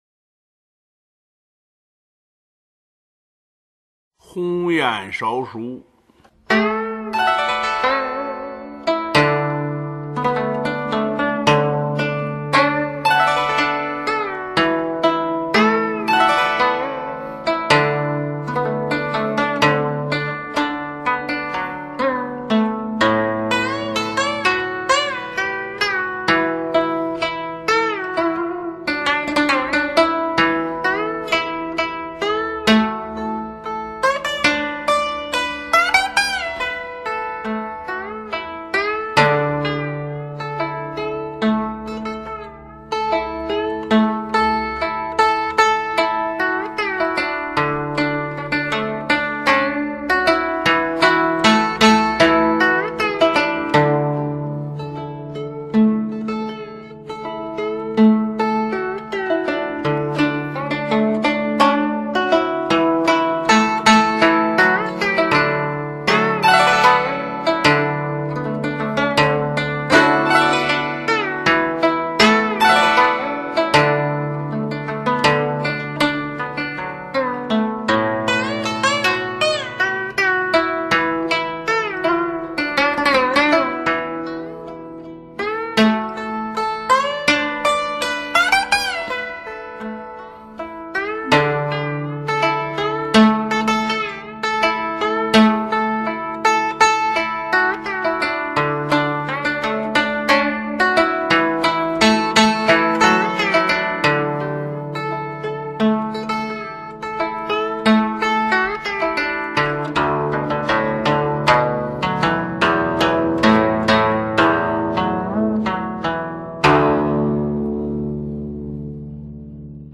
乐器：古筝
他的演奏风格纯朴热情，刚中有柔，乡土气息浓郁。